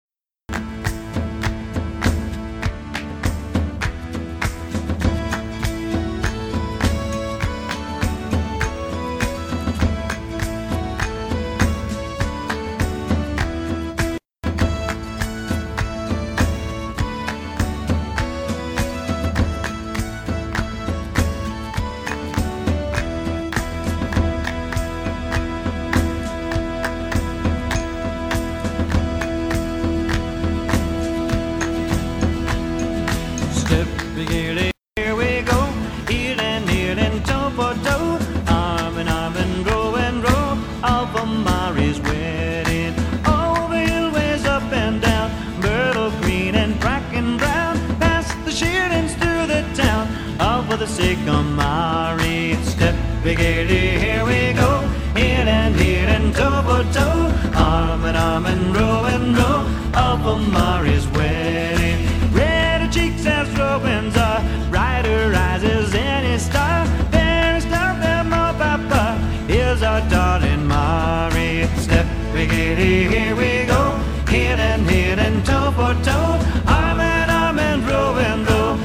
version courte et paroles
chant